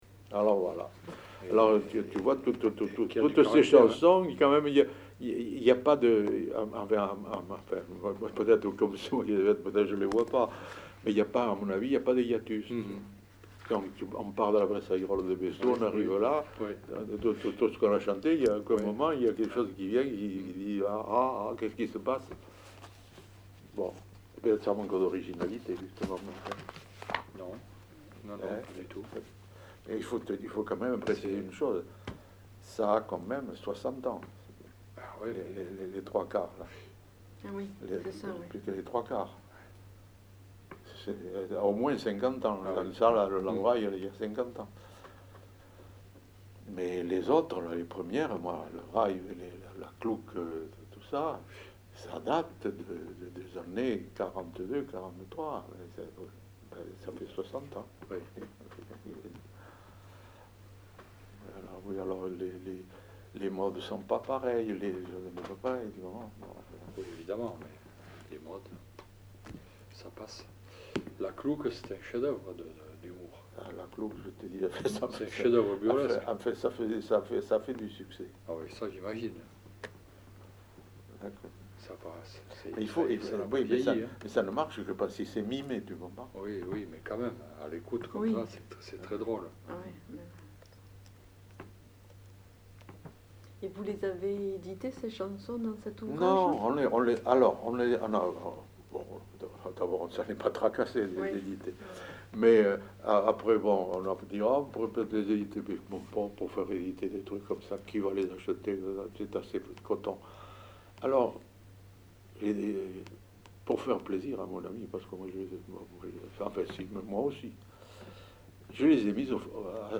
Aire culturelle : Rouergue
Lieu : Saint-Sauveur
Genre : témoignage thématique